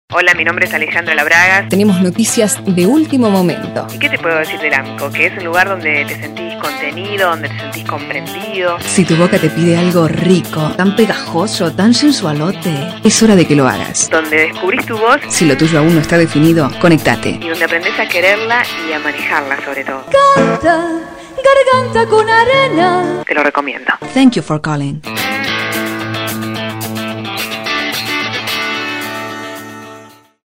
Sprecherin spanisch (Uruguay).
Sprechprobe: Werbung (Muttersprache):
female voice over talent spanish (uruguay)